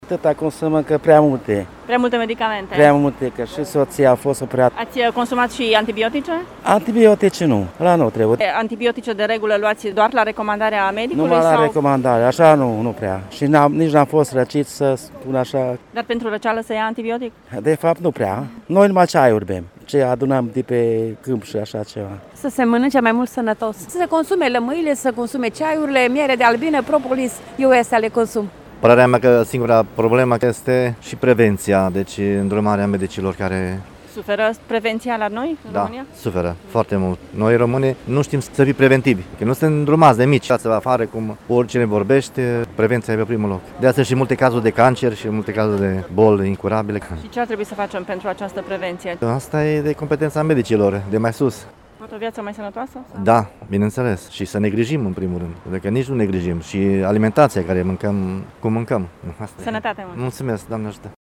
Târgumureșenii cred că principala problemă pentru aceste consumuri exagerate de antibiotice este lipsa prevenției și a educației pentru o viață sănătoasă încă de la vârste fragede: